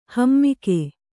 ♪ hamike